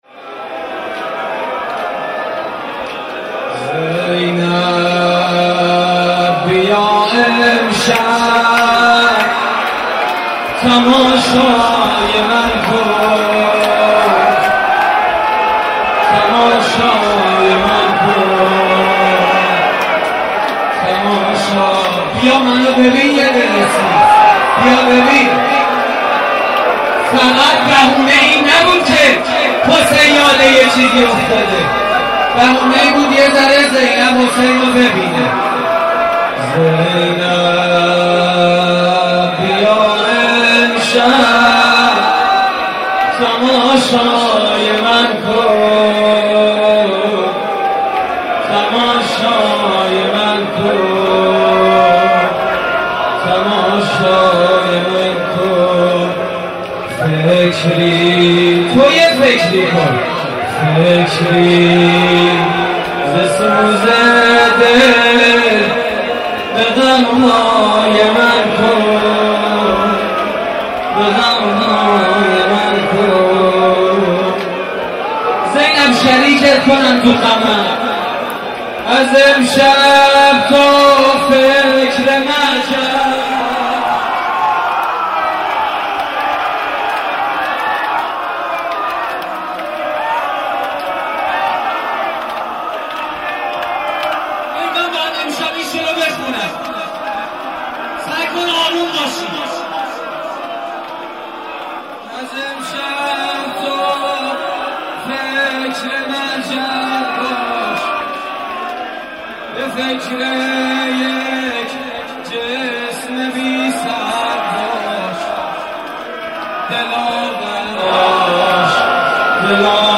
زمزمه: زینب بیا امشب
مراسم عزاداری شب عاشورای حسینی